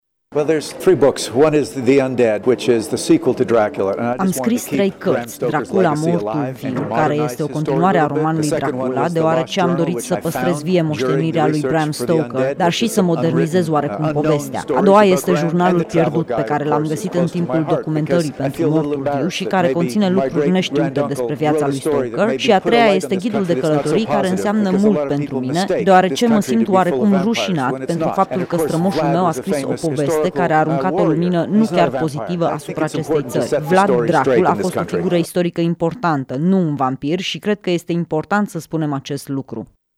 Nepotul scriitorului Bram Stoker, cel care a scris faimosul roman, a fost prezent, astăzi, la Salonul de Carte Bookfest de la Tîrgu-Mureş unde a susţinut o prelegere despre cum strămoşul său l-a creat pe contele vampir.